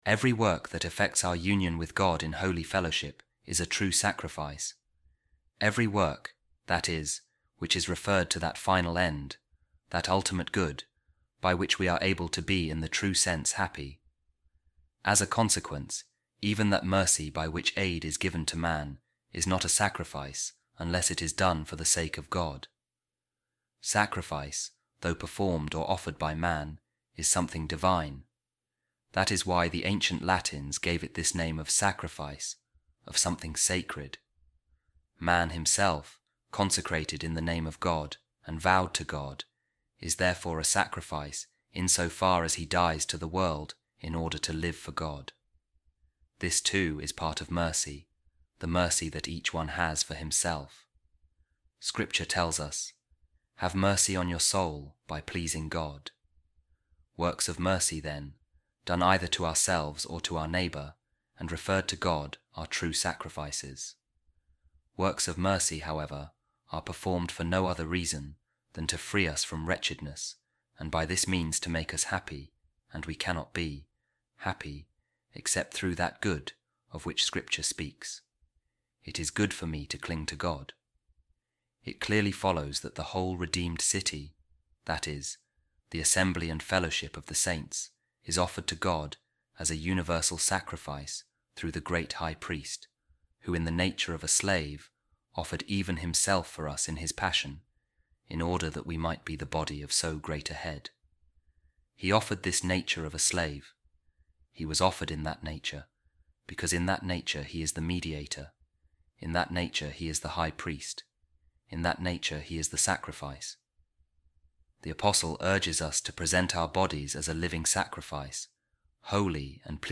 A Reading From The Book Of Saint Augustine The City Of God | In Every Place There Is A Sacrifice And A Clean Oblation Offered To My Name
office-readings-friday-28-saint-augustine-city-god.mp3